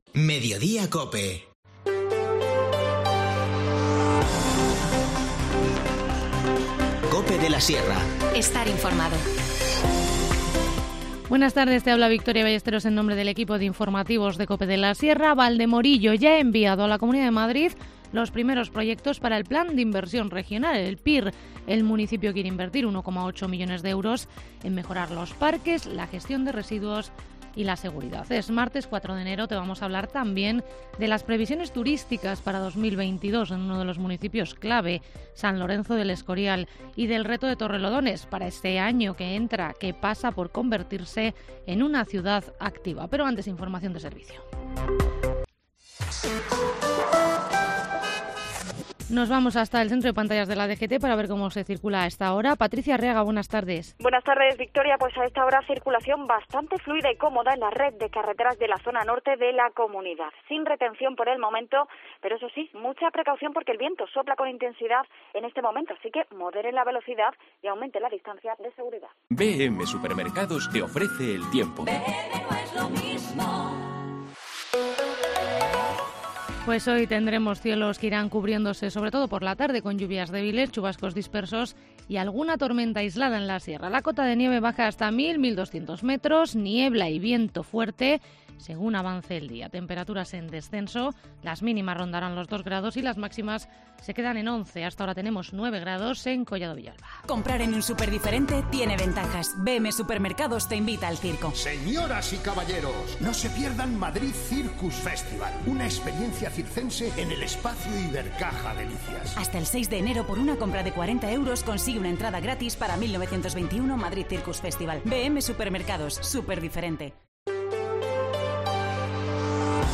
Informativo Mediodía 4 enero